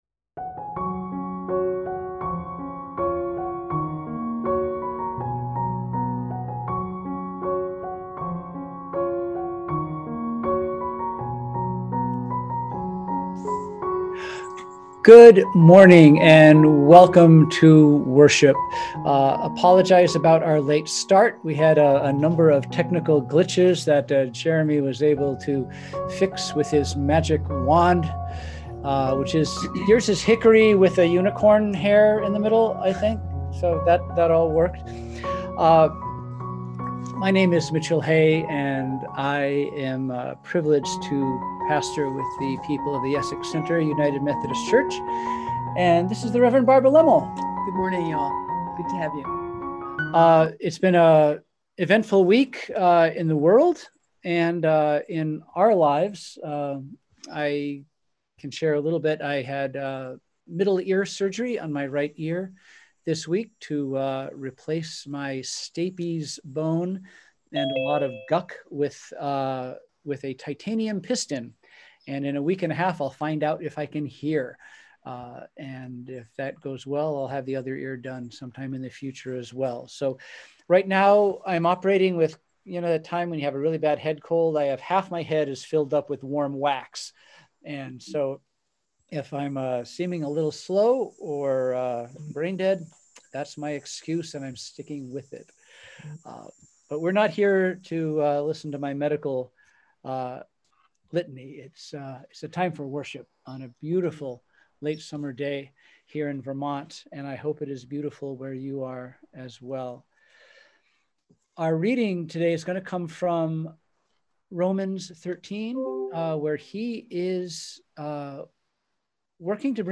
We held worship on Sunday, September 6, 2020 at 10am.